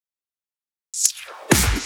Fill 128 BPM (24).wav